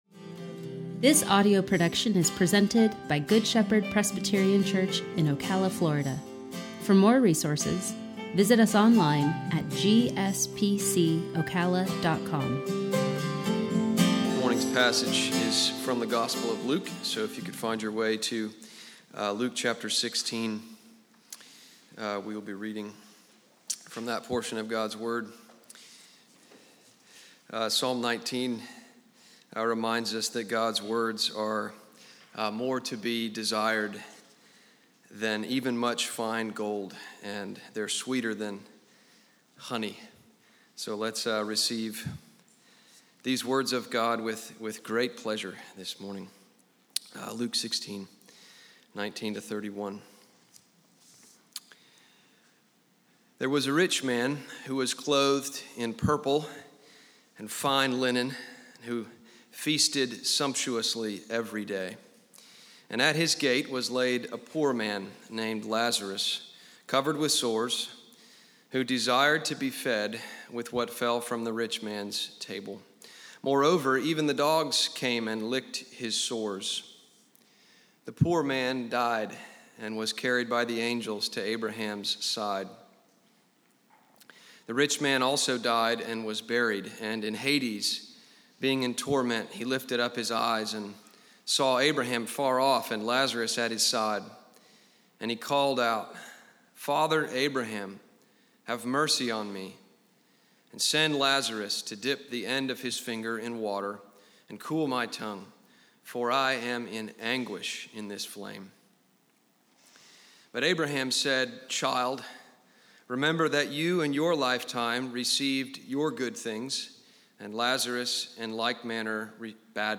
sermon-2-7-21.mp3